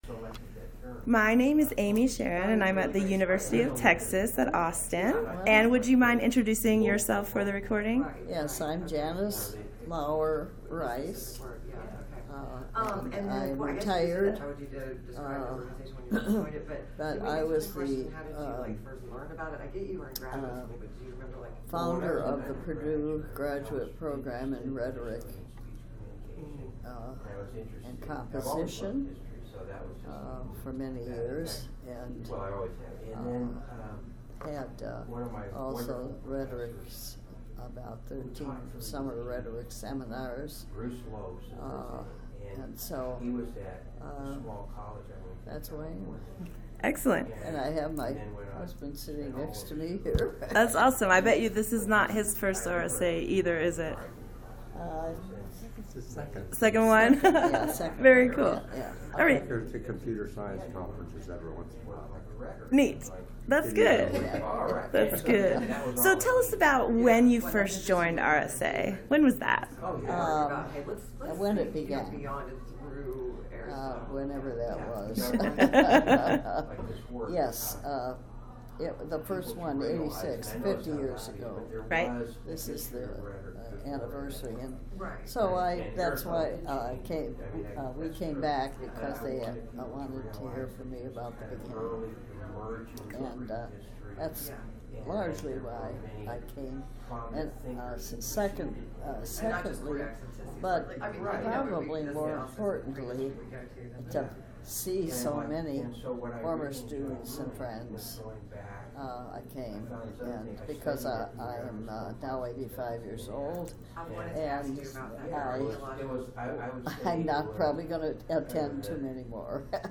Oral History
Location 2018 RSA Conference in Minneapolis, Minnesota